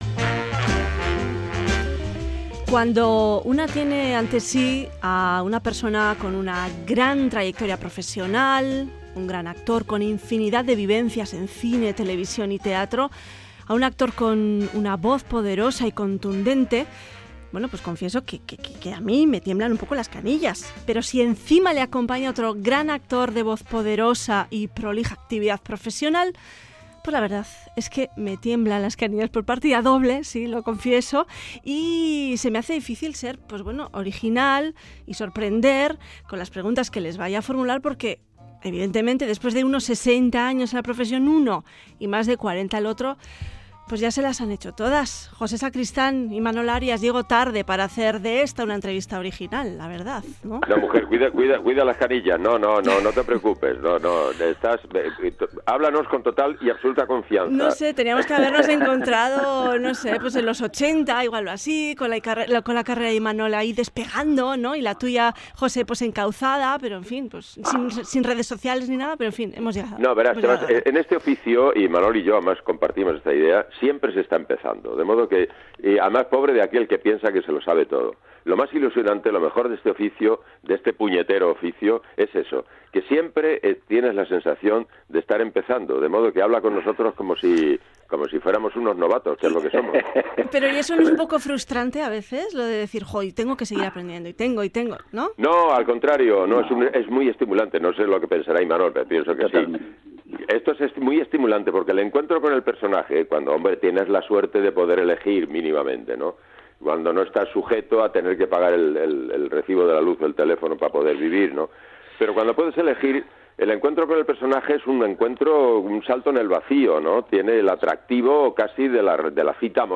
Audio: Entrevista con Imanol Arias y José Sacristán